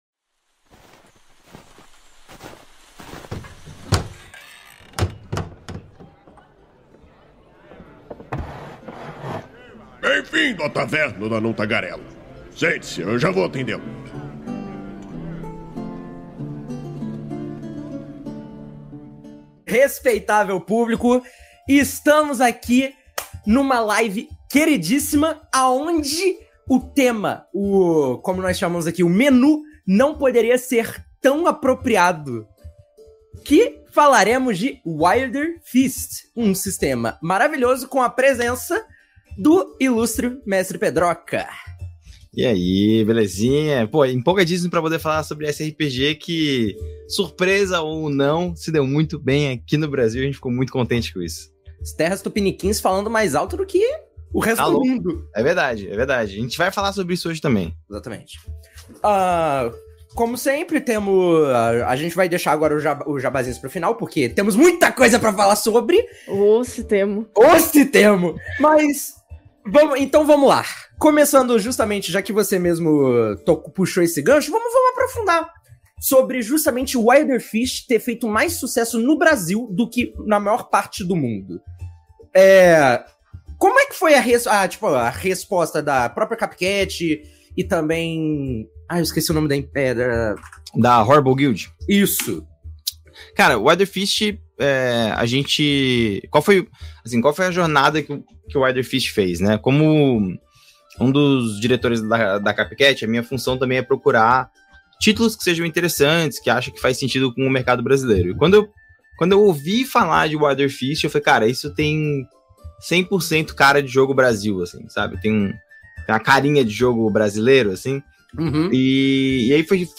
Venha saber mais sobre o que significa ser um feral, entenda um pouco mais sobre os mistérios que rondam a Terra Una e, o melhor de tudo, descubra um pouquinho do que vem ai para os biomas brasileiros em Wilderfeast. A Taverna do Anão Tagarela é uma iniciativa do site Movimento RPG, que vai ao ar ao vivo na Twitch toda a segunda-feira e posteriormente é convertida em Podcast.